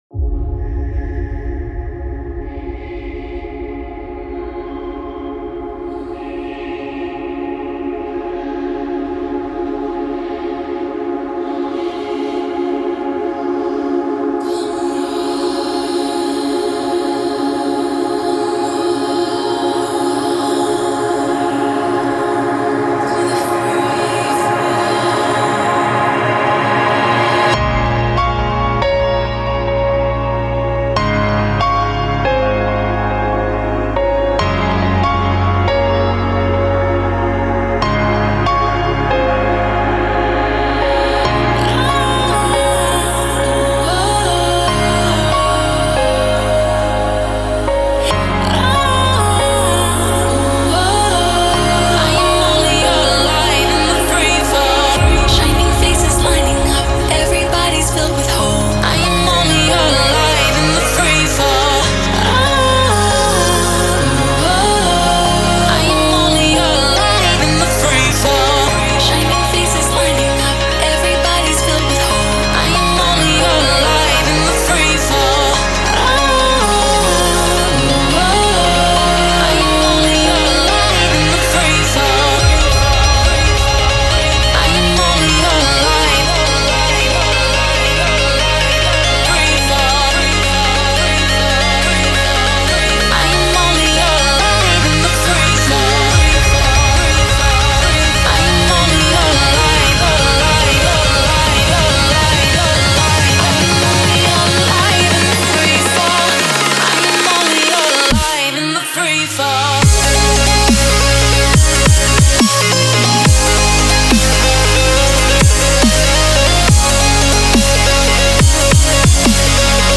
захватывающая электронная композиция